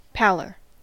Ääntäminen
Vaihtoehtoiset kirjoitusmuodot pallour Synonyymit paleness pallidity Ääntäminen US : IPA : [ˈpæ.lɚ] Tuntematon aksentti: IPA : /ˈpæ.lə(ɹ)/ Haettu sana löytyi näillä lähdekielillä: englanti Käännös Substantiivit 1.